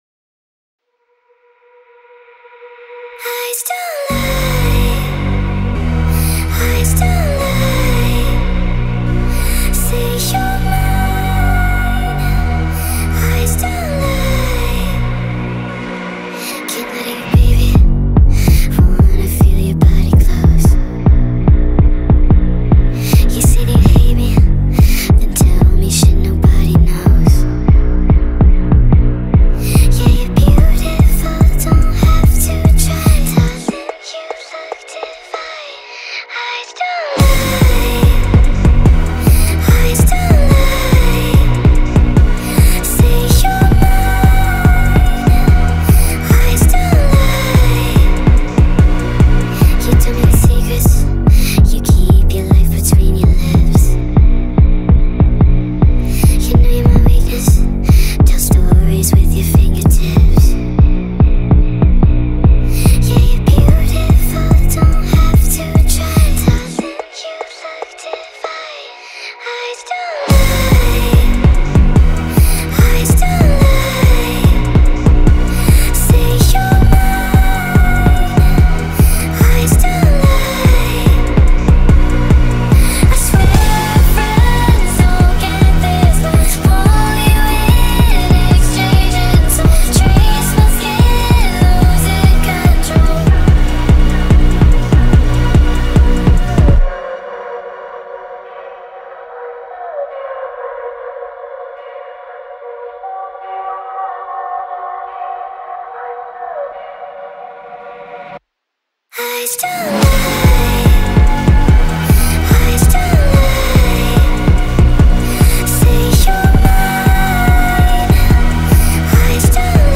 با ریتمی سریع شده
عاشقانه